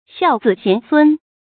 孝子贤孙 xiào zǐ xián sūn
孝子贤孙发音
成语正音 孙，不能读作“shūn”。